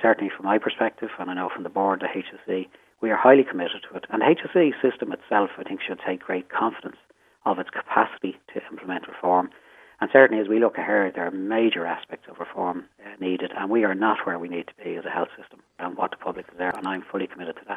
But HSE CEO Paul Reid says he fully backs the reforms plans: